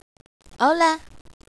Hello - Kia ora!